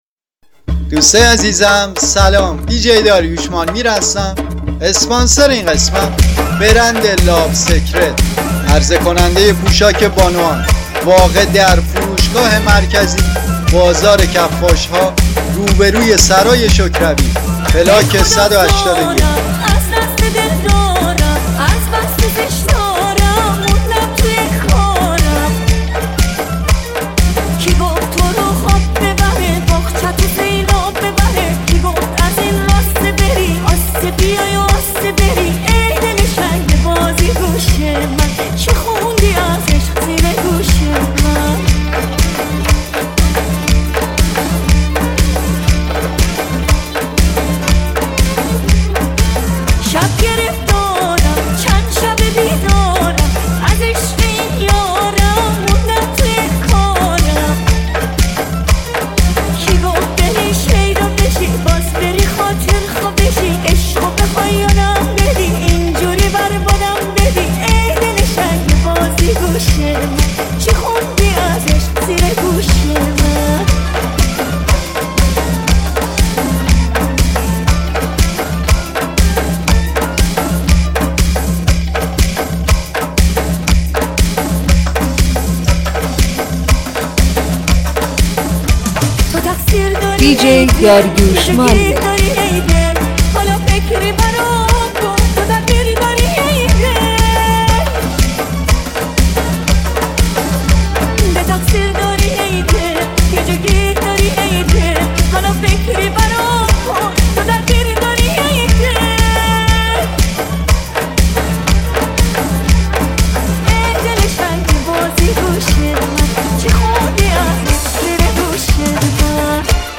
میکس 6.8 برای مهمانی و دورهمی
موزیک‌های 6.8 قری، مخصوص مهمونی و دورهمی‌هاتون!
موزیک ریمیکس